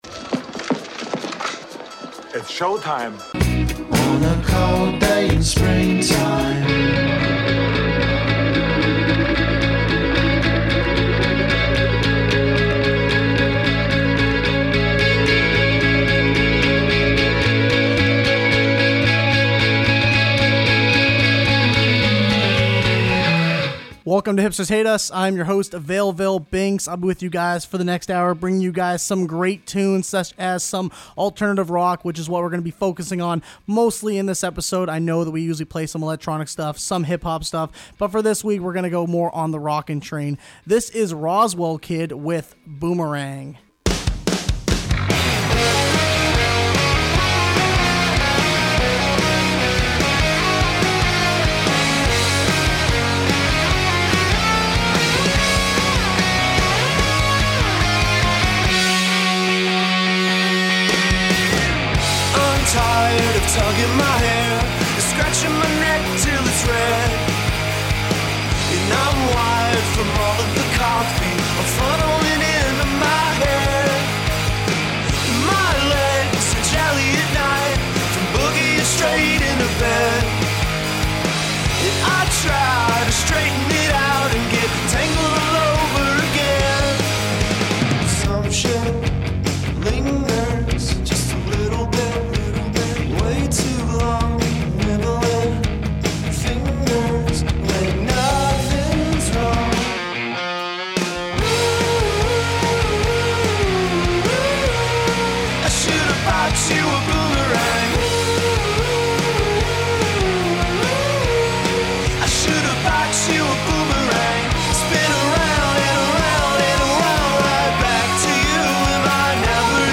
An Open Format Music Show. Britpop, Electronica, Hip-Hop, Alternative Rock, and Canadian music